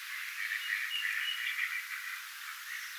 sinisorsakoiras?, mikä laji
olisiko_sinisorsakoiraan_soidinaania_mika_laji.mp3